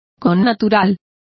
Complete with pronunciation of the translation of inborn.